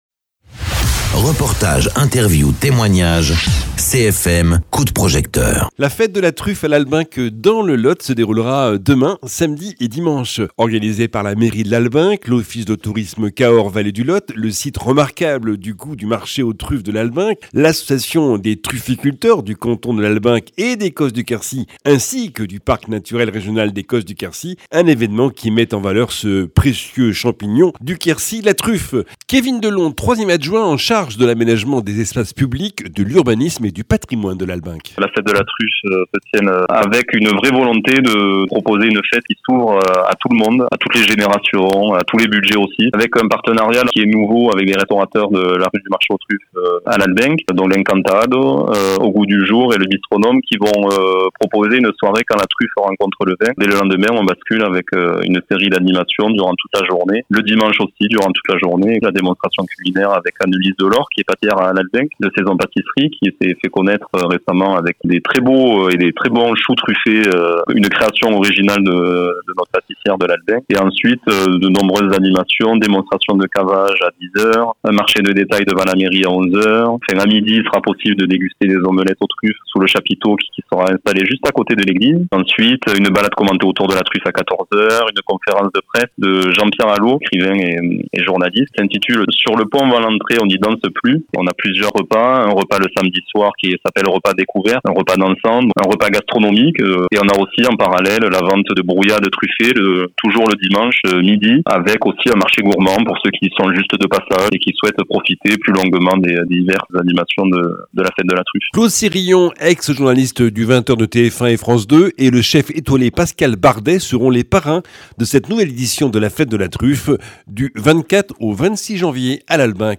Interviews
Invité(s) : Kevin Delon, 3ème adjoint en charge de l’aménagement des espaces publics, de l’urbanisme et du patrimoine de Lalbenque